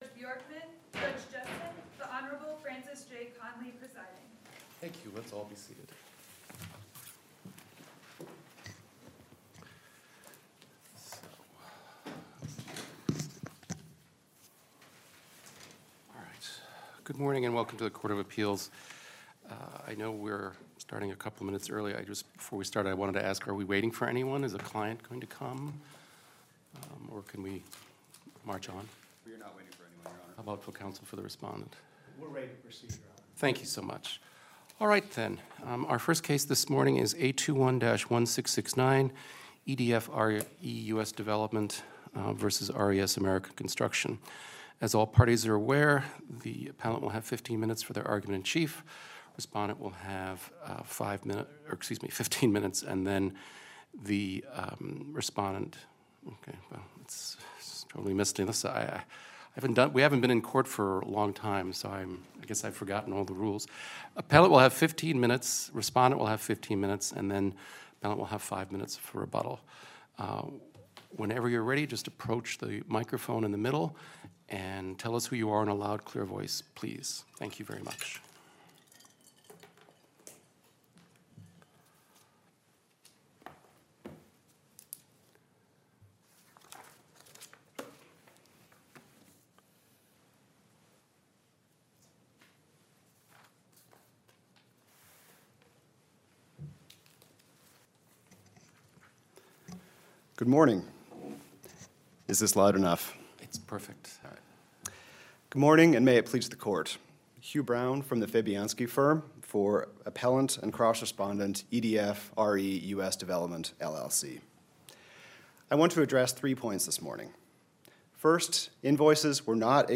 Minnesota Court of Appeals Oral Argument Audio Recording